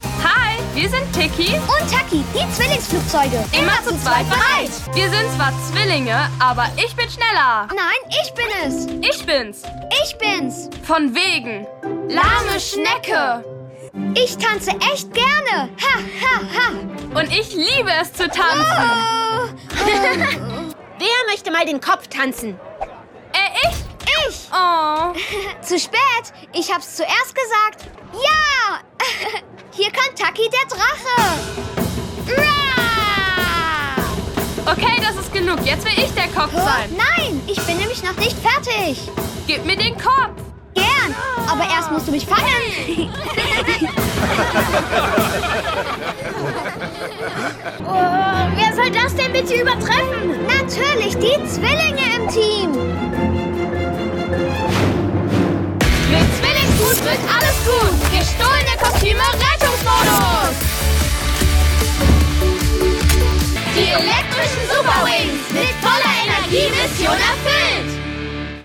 Erzählung